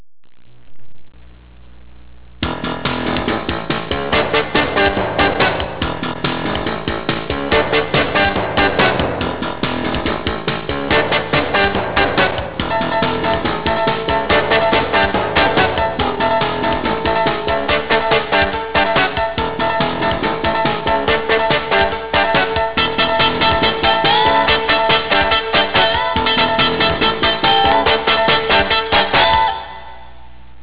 There's a demo version to sing this song all together.
It's only a cheap demo, but enough to hum.